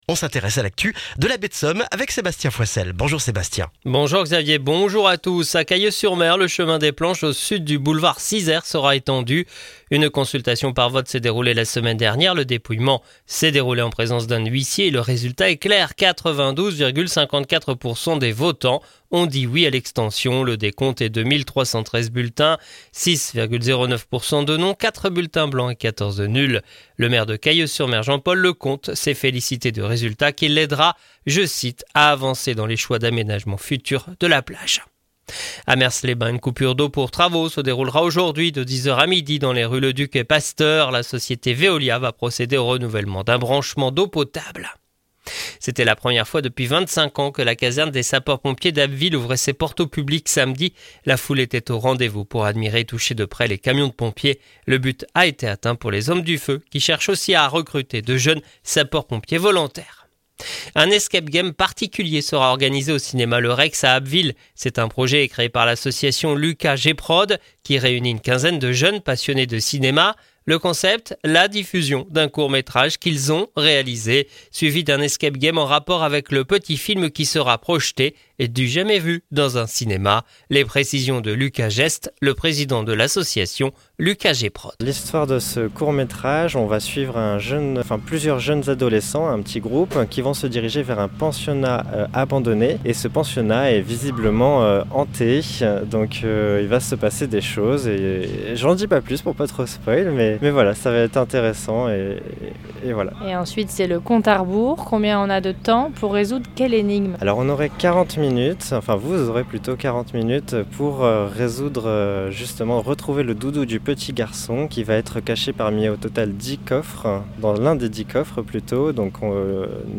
Le journal du lundi 7 octobre en Baie de Somme et dans la région d'Abbeville